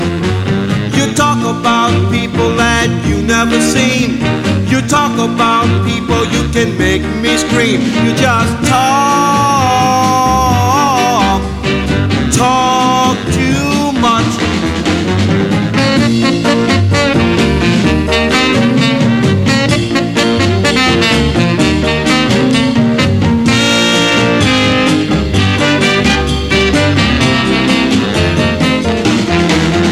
Жанр: Поп музыка / Рок / R&B / Соул